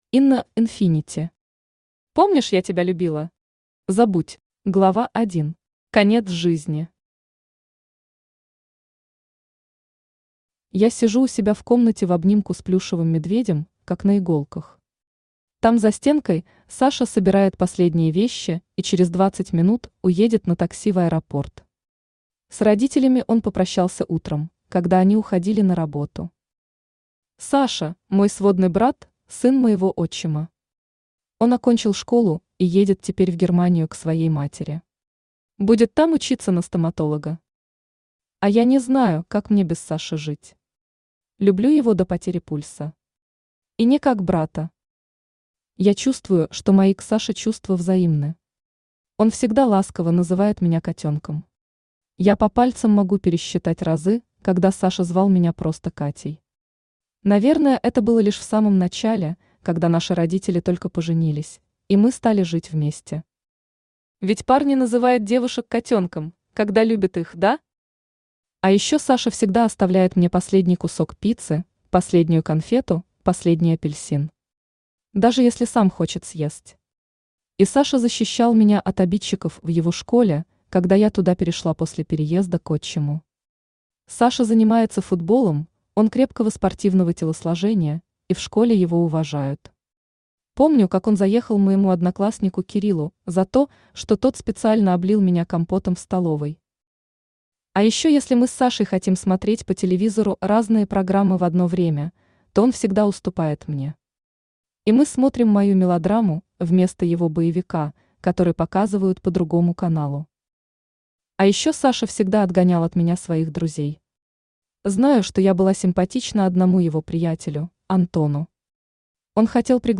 Аудиокнига Помнишь, я тебя любила? Забудь!
Автор Инна Инфинити Читает аудиокнигу Авточтец ЛитРес.